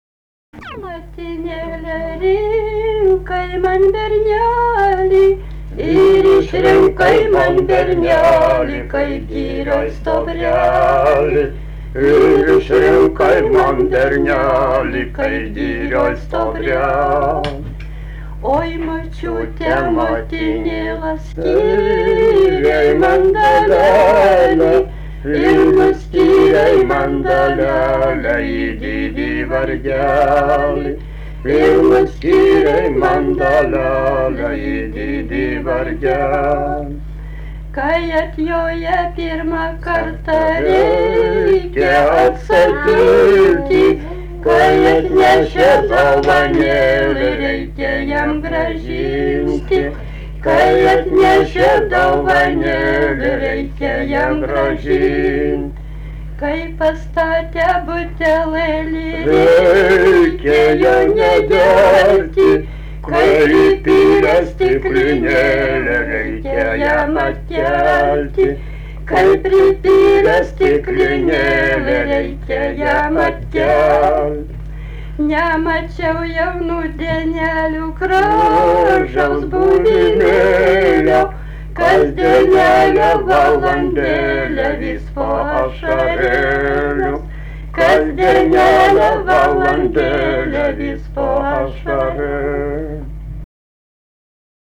daina, vestuvių
Vabalninkas
vokalinis